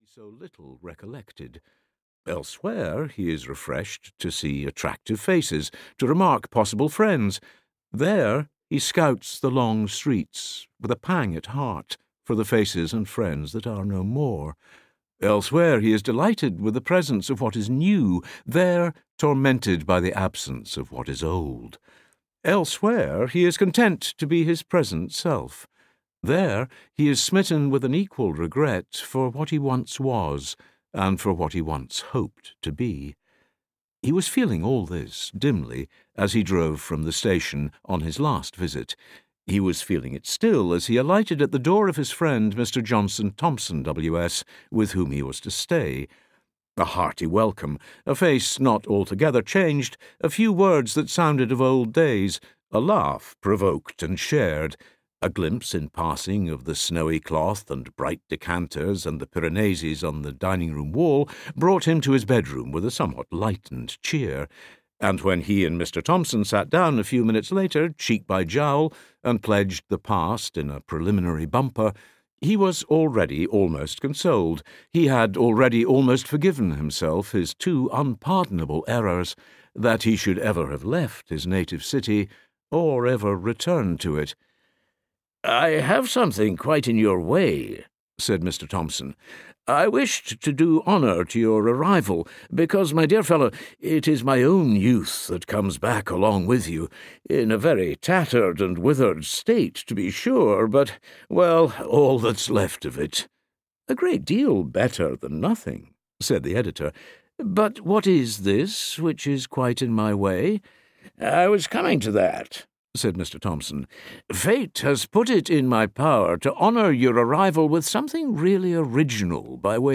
The Master of Ballantrae (EN) audiokniha
Ukázka z knihy
• InterpretDavid Rintoul